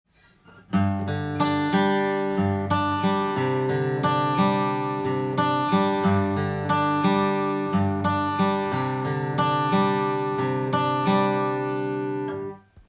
エリクサーのアコギ弦１２～５３はこんな音でした
弦が太い為　音も太く安定は抜群です。